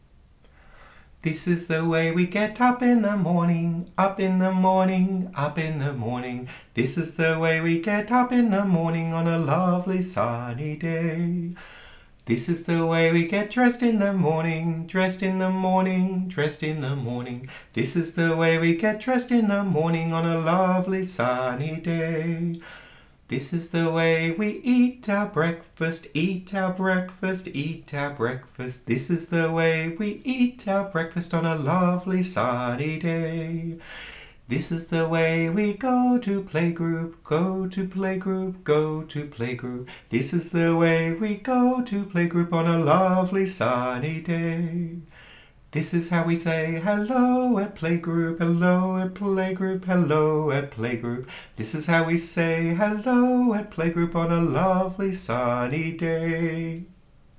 Song -